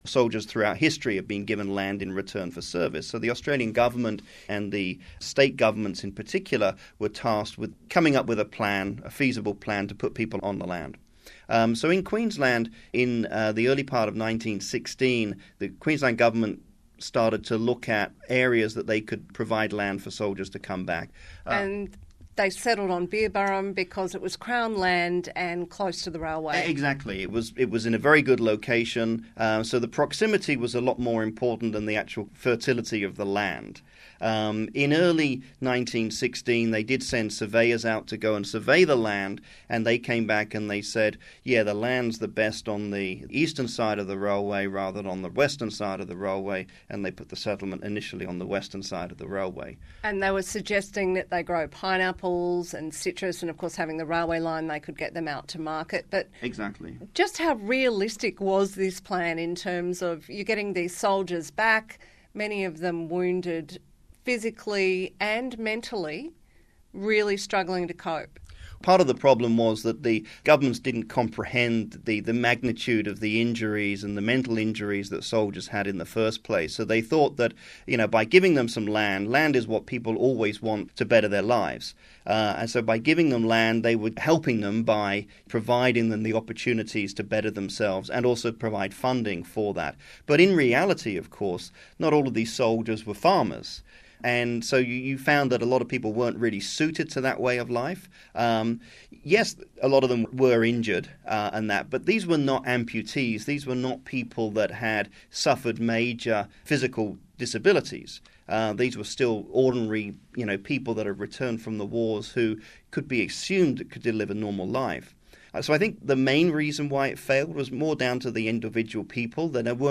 Interview concerning the Beerburrum Soldier Settlement on ABC Sunshine Coast and ABC Queensland.
Beerburrum Soldier Settlement interview